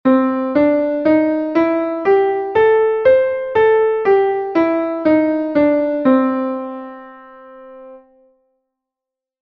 blues_maior.mp3